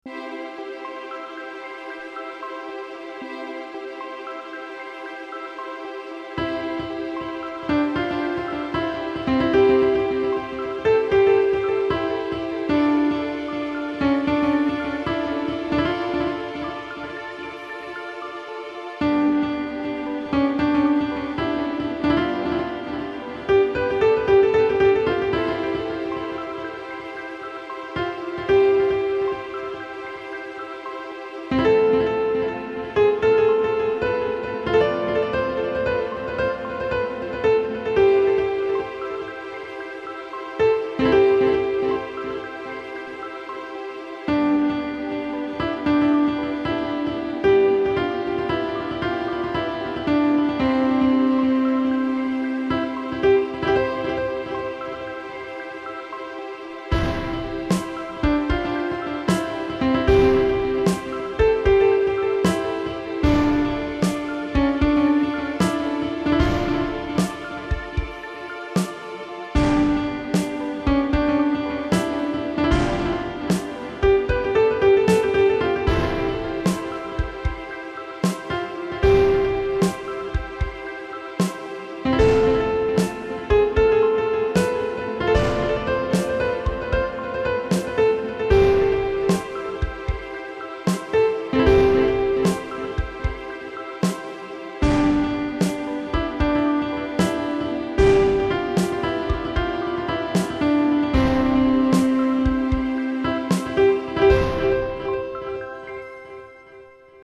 Melodierne fejler for saa vidt ikke noget, men de er helt sikkert ikke godt arrangeret.